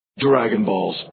Download Dragon Ball sound effect for free.